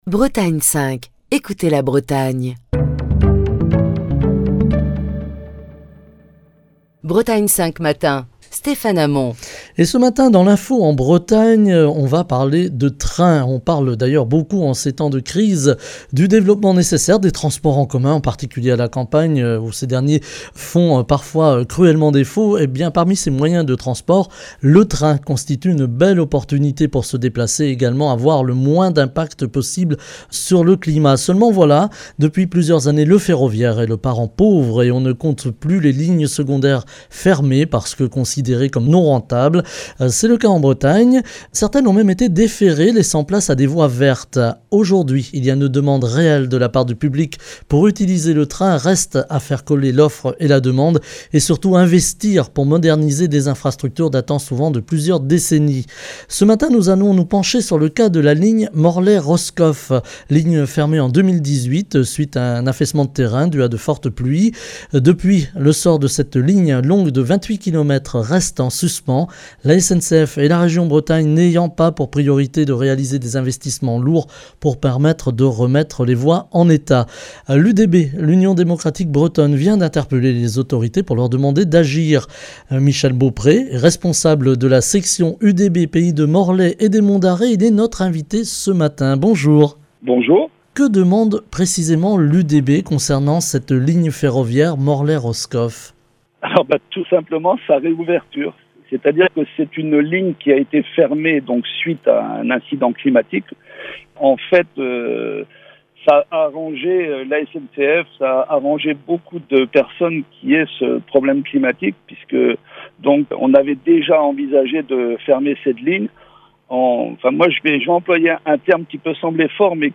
Émission du 1er septembre 2022.